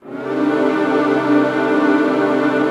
06PAD 01  -R.wav